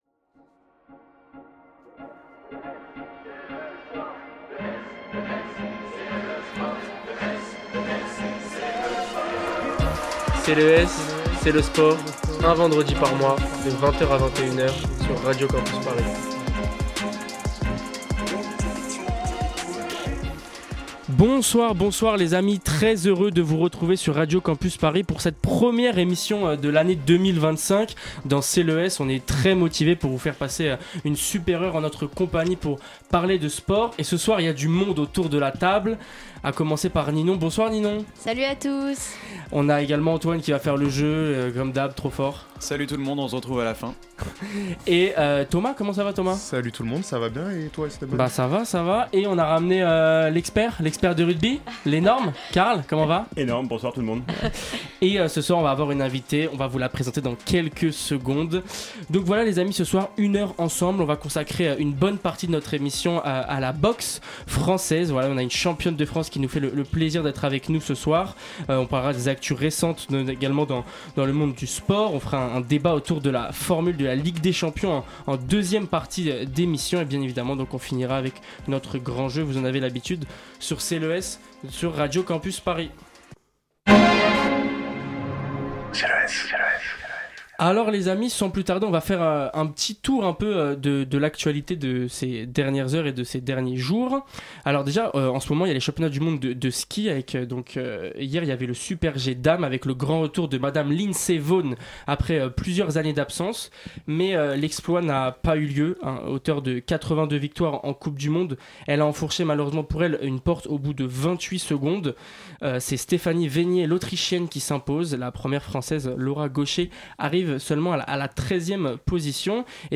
Type Magazine Sport